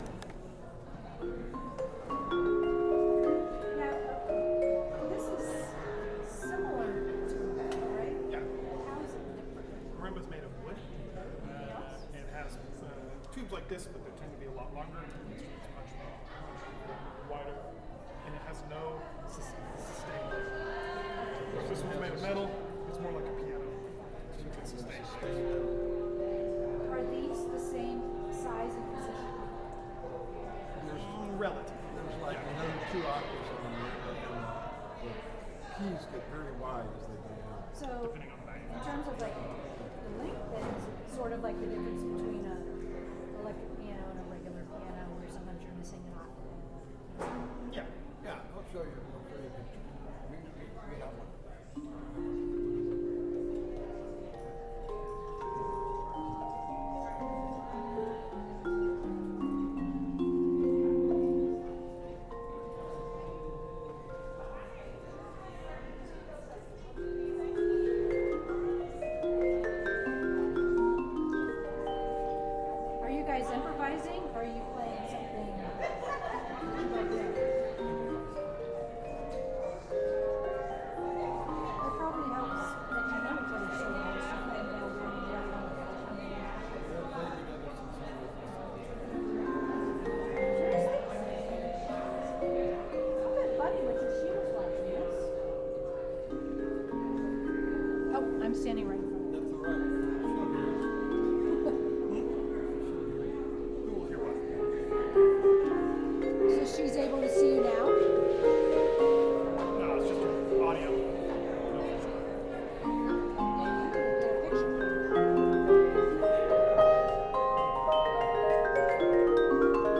Improvisations
piano
vibes